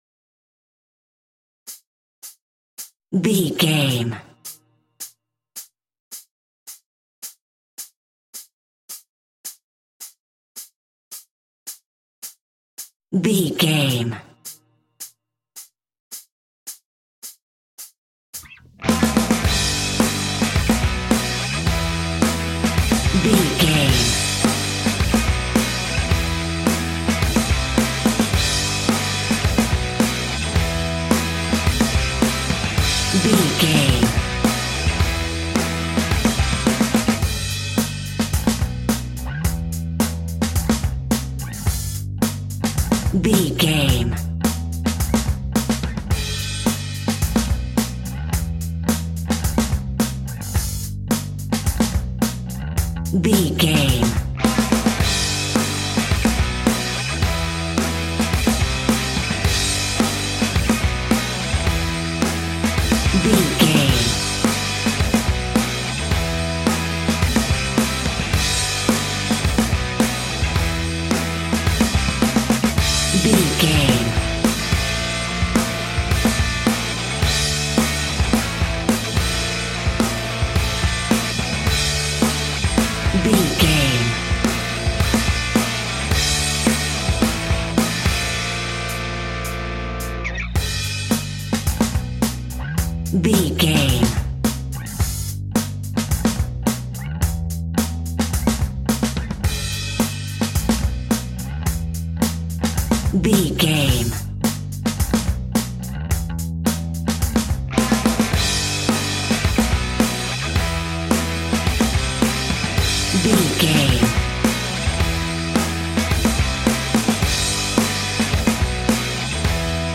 Epic / Action
Ionian/Major
hard rock
heavy metal
dirty rock
rock instrumentals
Heavy Metal Guitars
Metal Drums
Heavy Bass Guitars